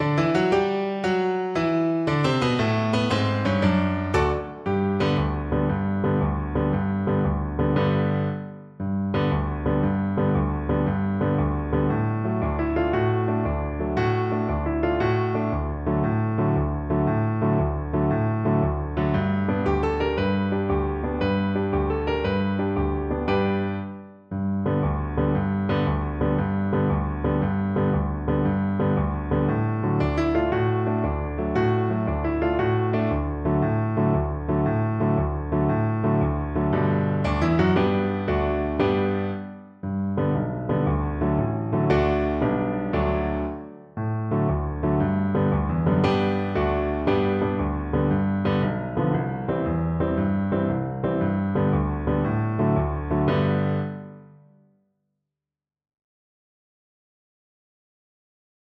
Play (or use space bar on your keyboard) Pause Music Playalong - Piano Accompaniment Playalong Band Accompaniment not yet available transpose reset tempo print settings full screen
Cello
"Luna mezz'o mare" ("Moon amid the sea") is a comic Neapolitan song with worldwide popularity, traditionally styled as a brisk 6/8 tarantella, based on an earlier version from Sicily.
6/8 (View more 6/8 Music)
G major (Sounding Pitch) (View more G major Music for Cello )
Allegro . = 116 (View more music marked Allegro)
Traditional (View more Traditional Cello Music)